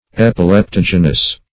Search Result for " epileptogenous" : The Collaborative International Dictionary of English v.0.48: Epileptogenous \Ep`i*lep*tog"e*nous\, a. [Gr.
epileptogenous.mp3